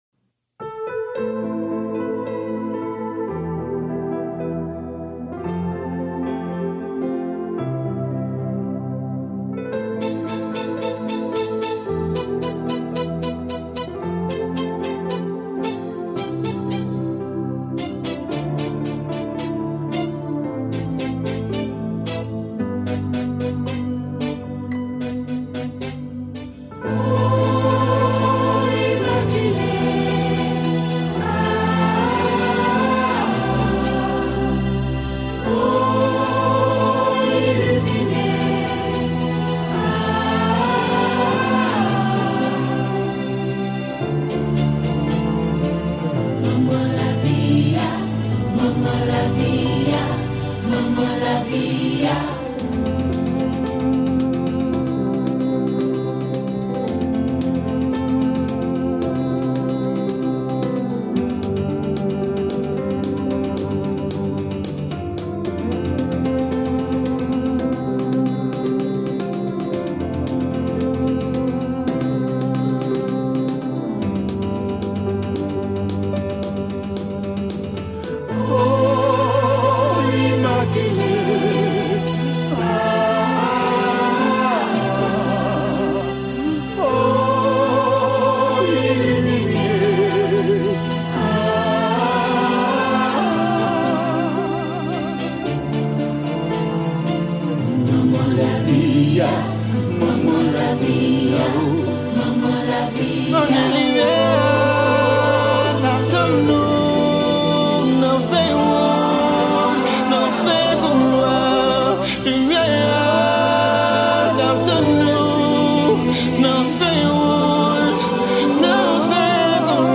Genre: Racine.